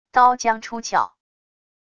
刀将出鞘wav音频